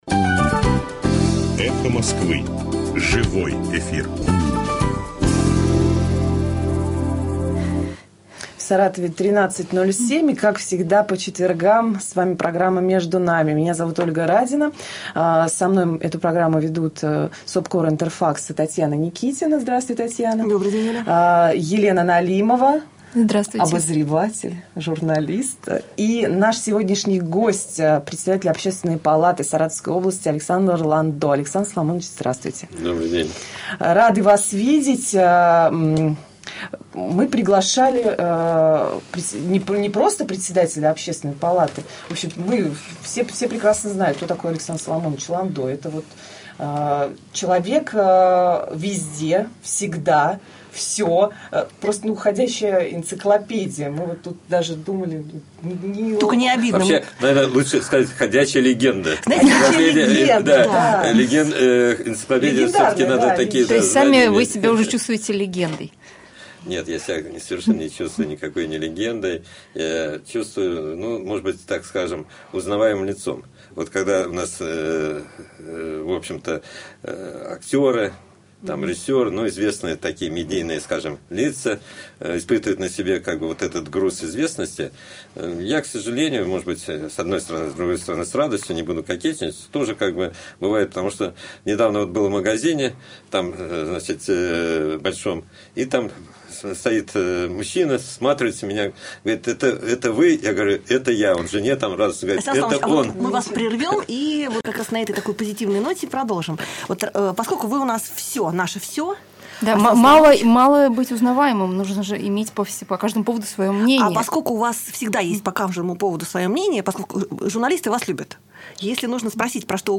Запись эфира от 21 февраля 2013 года.